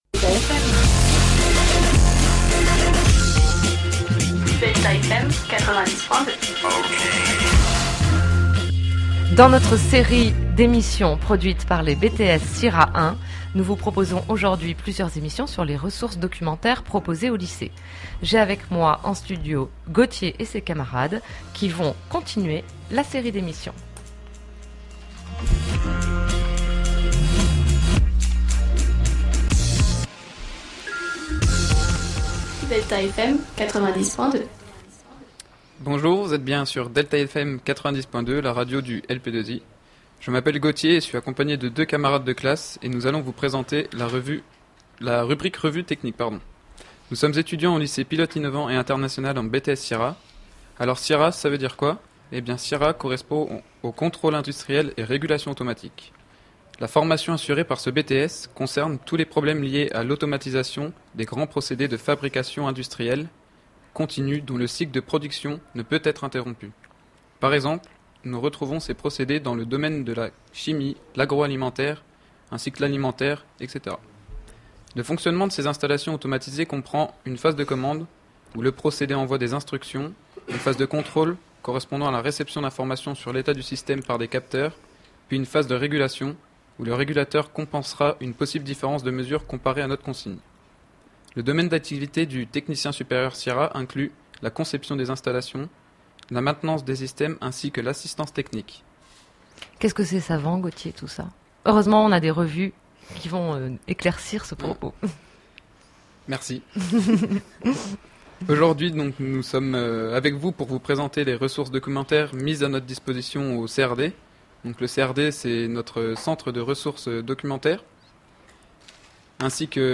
Les étudiants (scolaires et apprentis) de CIRA 1 présentent, en direct sur DELTA FM, les ressources du CRD : Micro-hebdo, Usine Nouvelle, Planète Robots, Mesures...